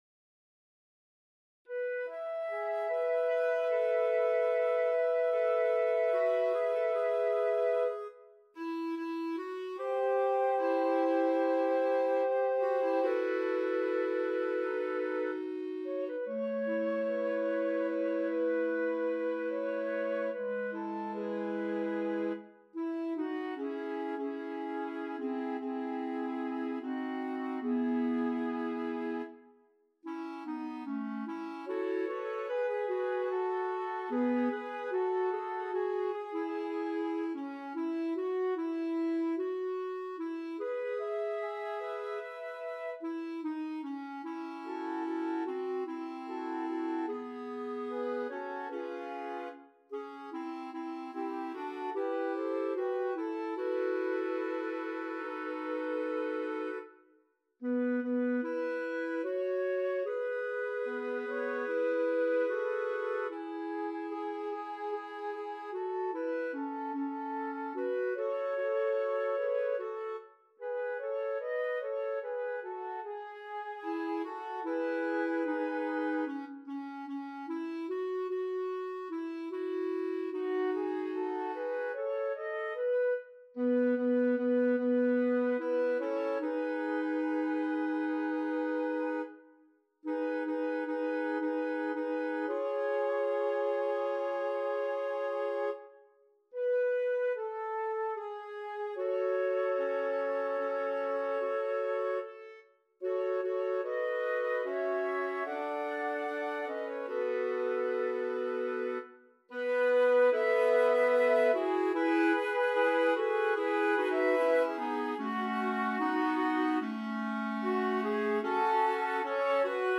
4-part female choir, SSAA a capella
世俗音樂